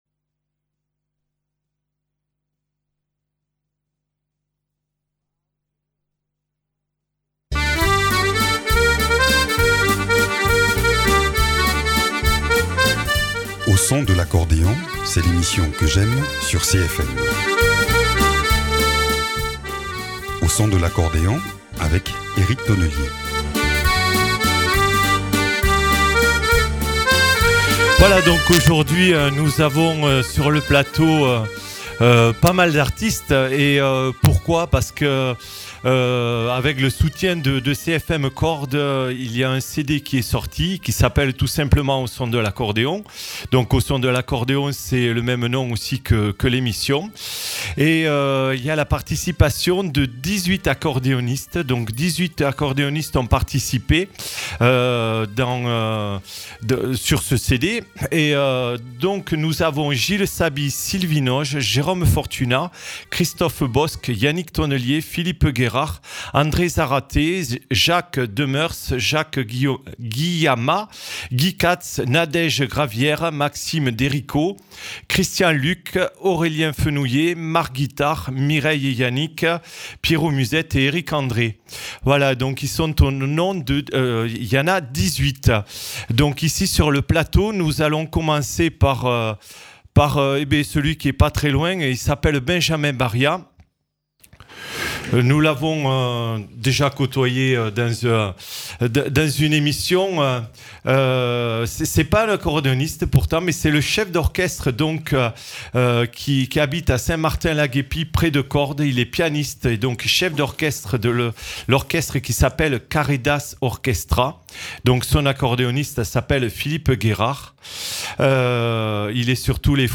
Découvrez et célébrez avec nous la sortie d’un CD sous forme de compilations à partir de titres d’invités reçus ces dernières années dans l’émission. 23 titres au total qui offrent un tour d’horizon de la dynamique de l’accordéon dans la région.